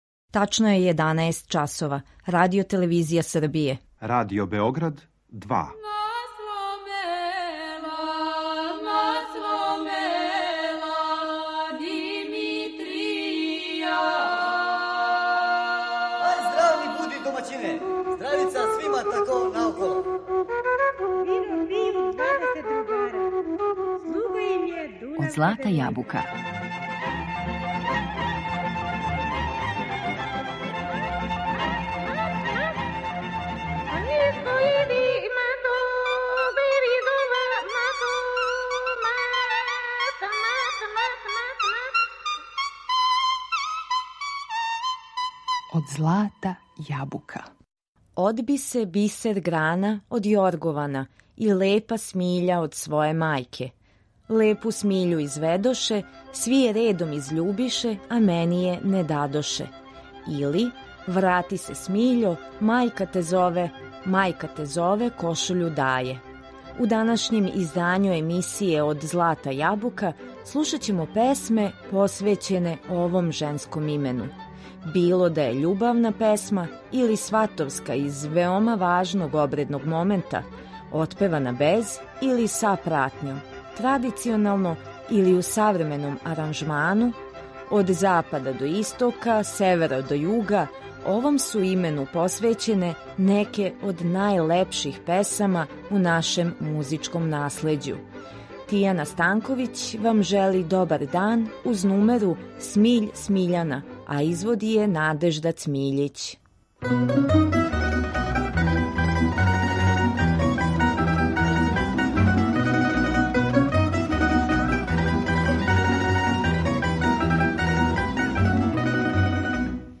Осим различитих варијанти ове нумере која се данас ретко изводи у оквиру свадбеног церемонијала, на репертоару су и савремене обраде традиционалних песама посвећених Смиљани и компоноване песме у народном духу.